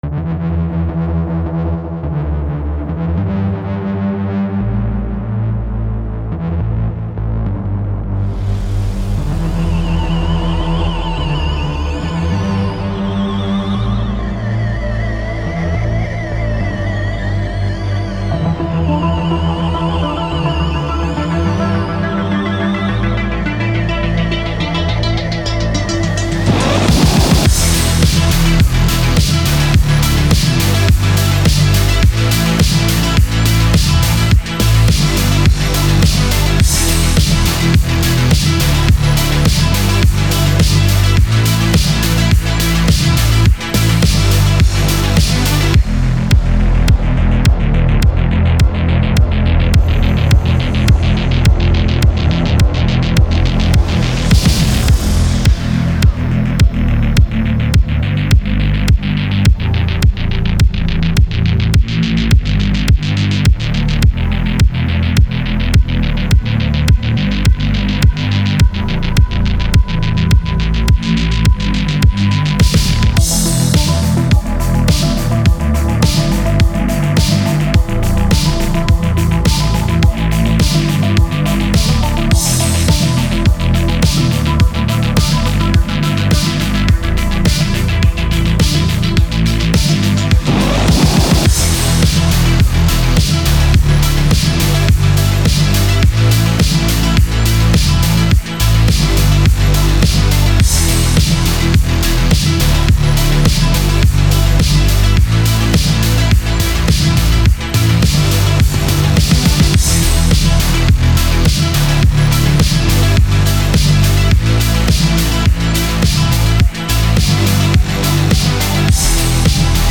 • House
• Chill Out
• Indie Dance
• Nu Disco